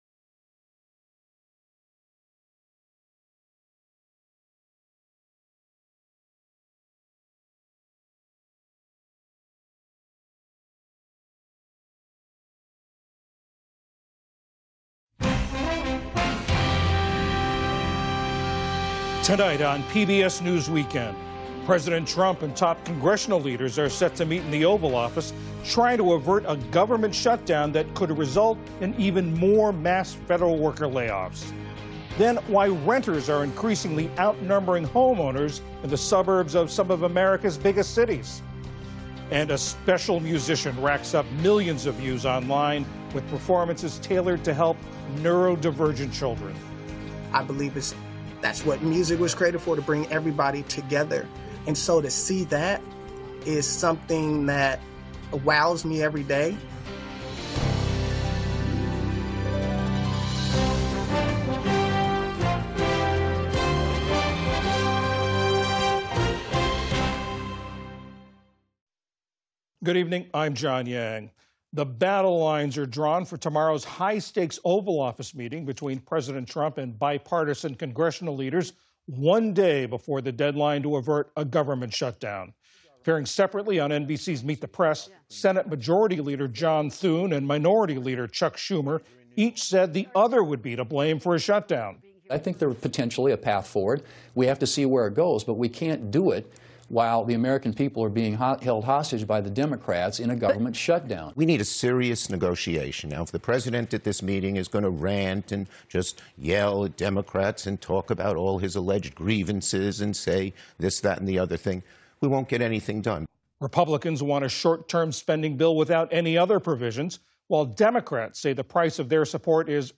News, Daily News